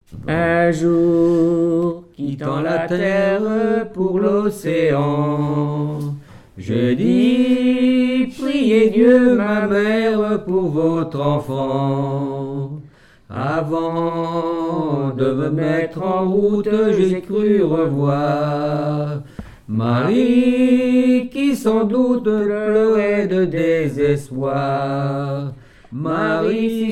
circonstance : maritimes
Genre strophique
Pièce musicale inédite